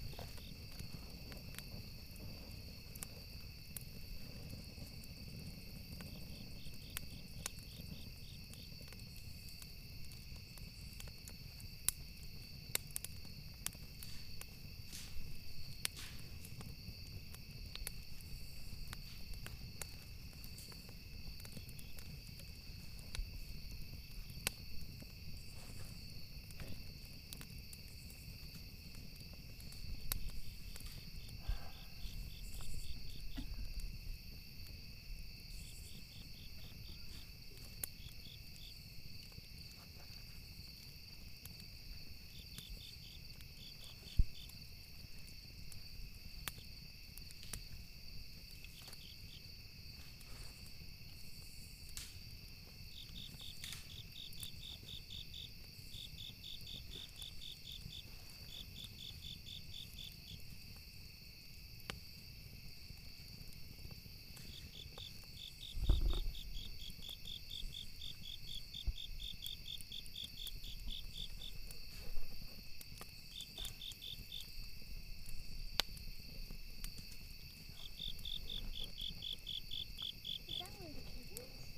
campfire.ogg